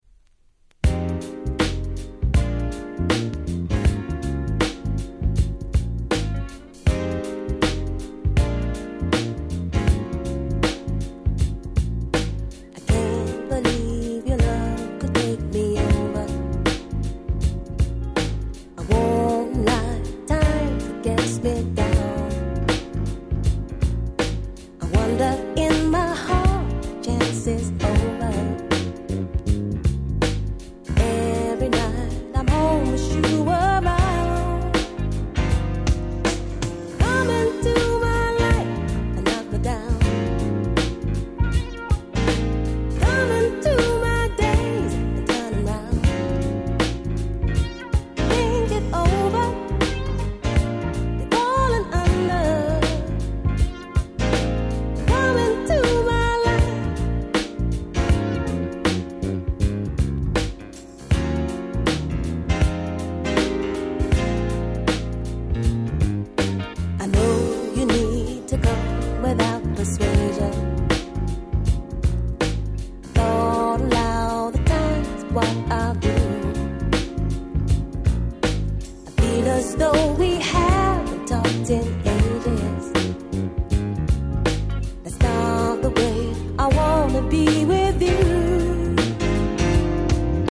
94' Nice UK Soul !!
Neo Soulな感じのまったりとした素晴らしいUK Soul。